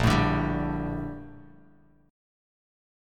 A#M13 Chord
Listen to A#M13 strummed